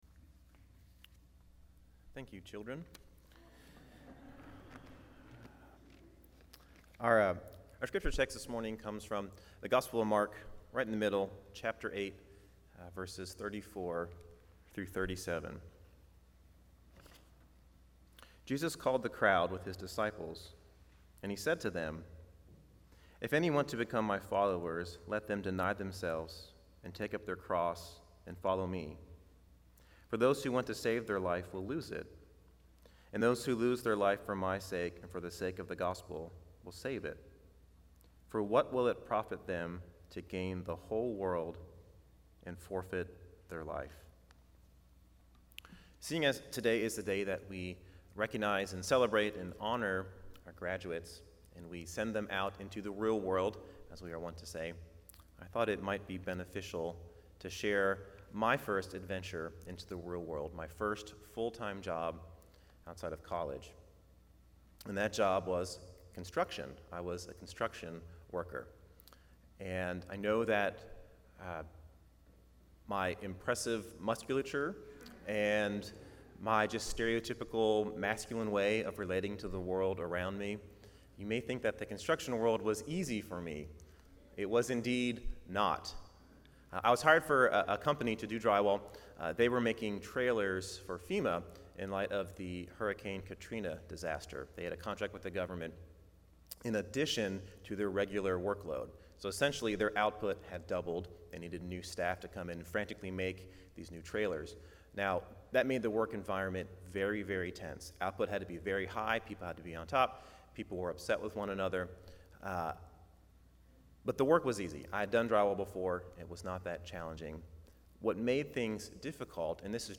Passage: Mark 8:34-37 Service Type: Guest Preacher Bible Text